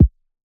shitty kick.wav